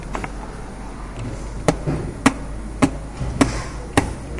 描述：由R09记录的爆震
Tag: 动物